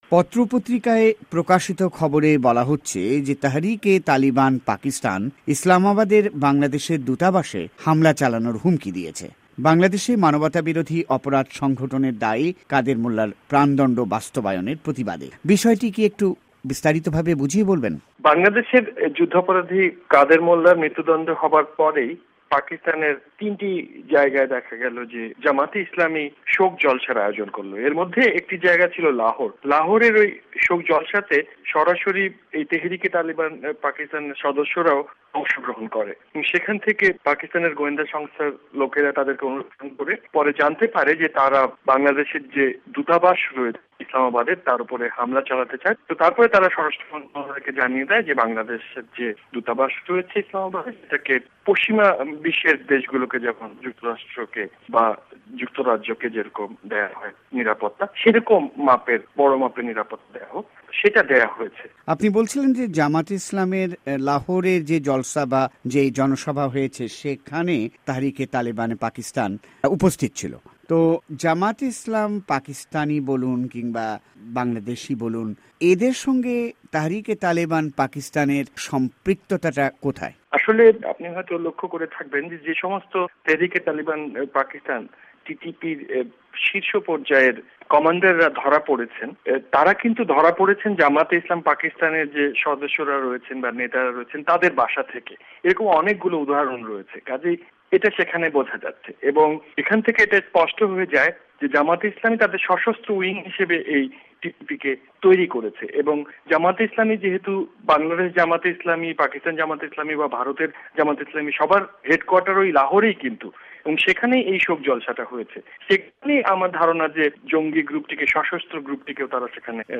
এক টেলিফোন সাক্ষাৎকারে